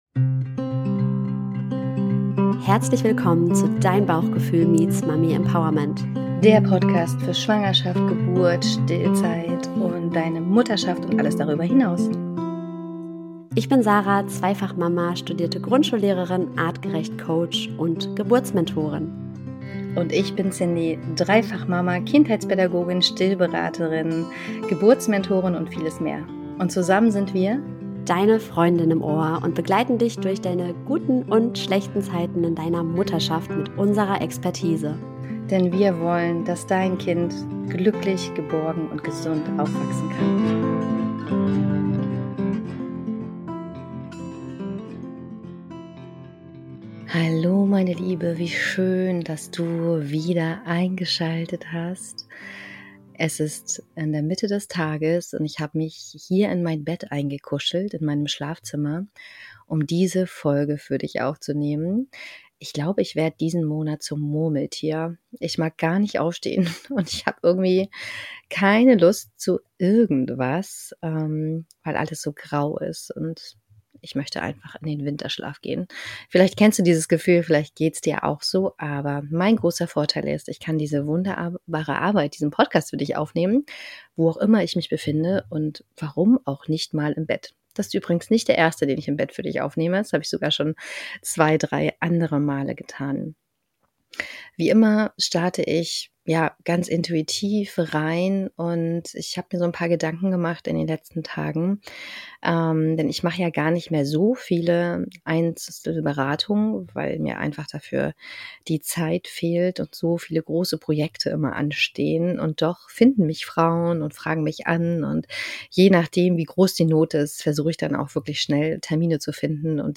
Diese Folge kommt aus meinem Bett – und sie ist voll mit Emotionen, echten Erkenntnissen und vielleicht genau den Worten, die du gerade brauchst.